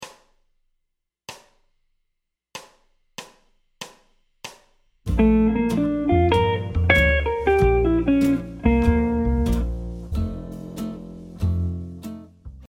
Tonalité du morceau : Bb
Séquence de la phrase : I vers V7